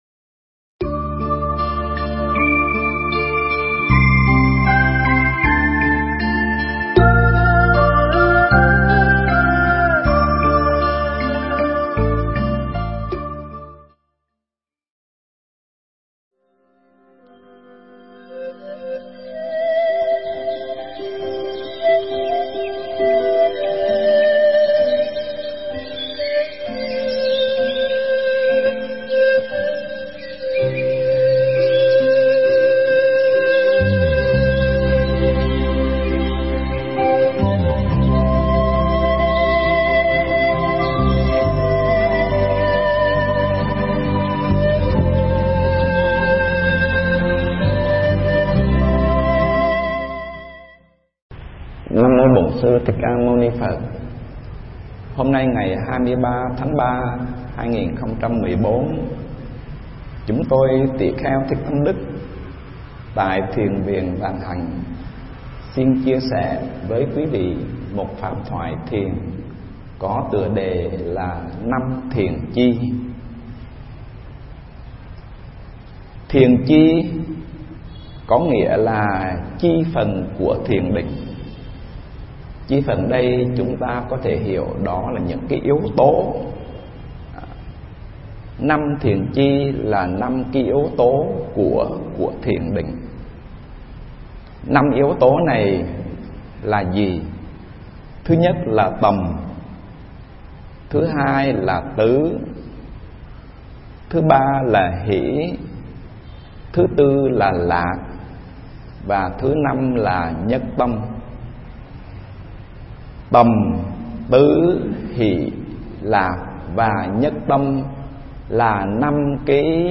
Nghe Mp3 thuyết pháp 5 Thiền Chi